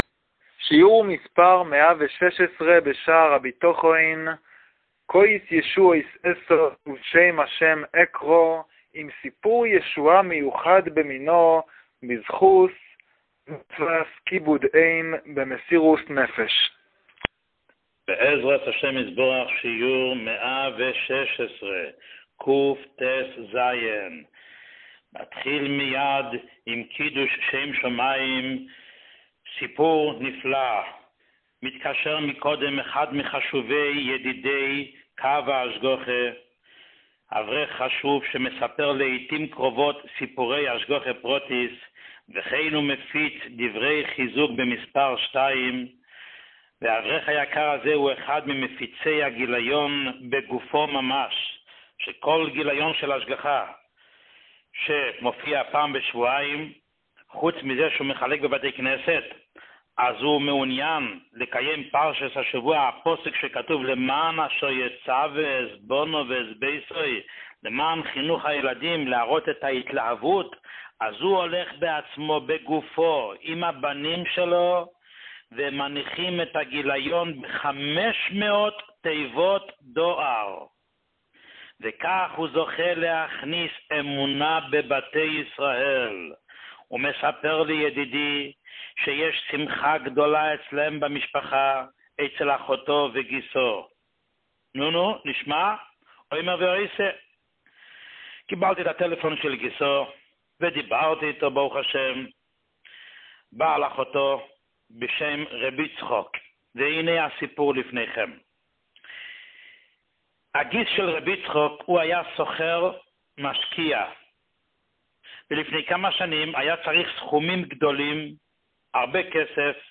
שיעור 116